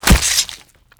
tinyblade.wav